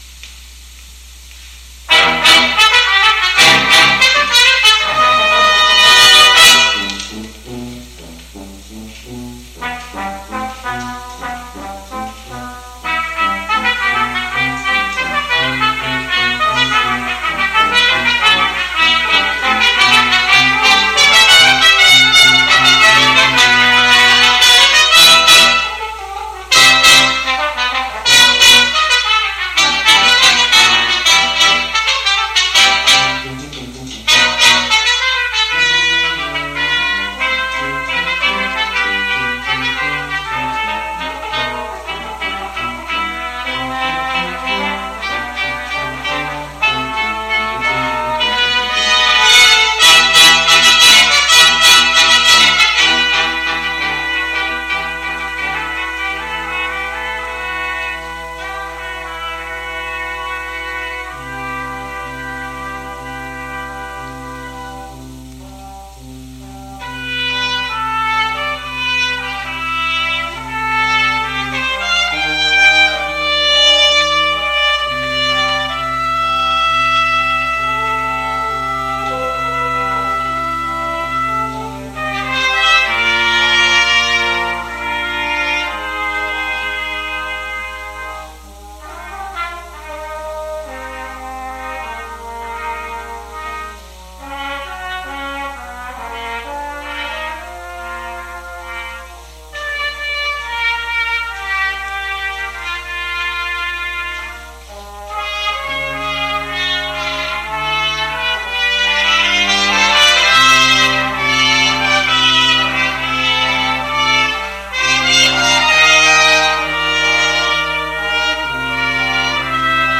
Coup de Langue for Brass Quintet - MP3